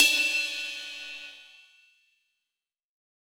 HRIDE 2Y.wav